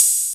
Open Hat [11].wav